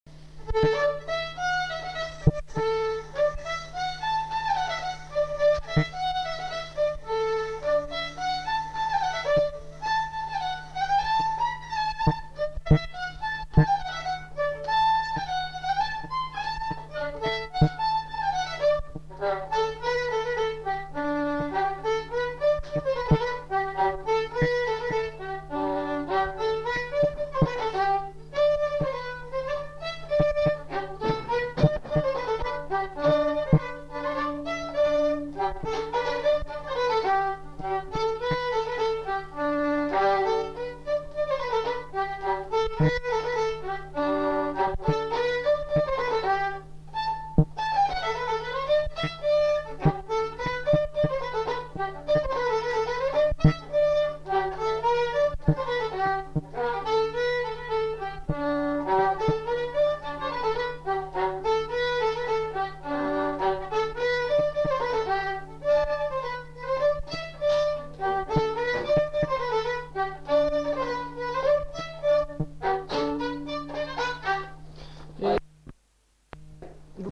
Lieu : Saint-Michel-de-Castelnau
Genre : morceau instrumental
Instrument de musique : violon
Danse : congo
Notes consultables : 2 violons.